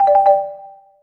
collect_item_10.wav